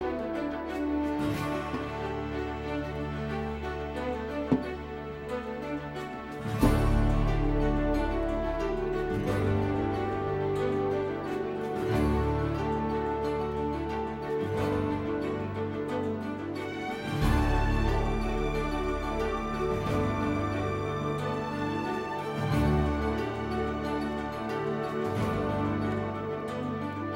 On this page, I have only 5 sequence examples taken from actual movies.